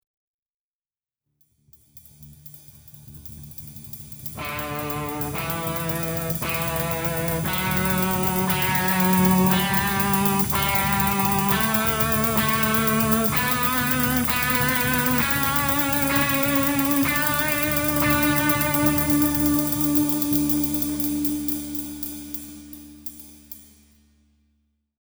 Then, try to sing each pitch in the scale before playing it, as shown in FIGURE 1b.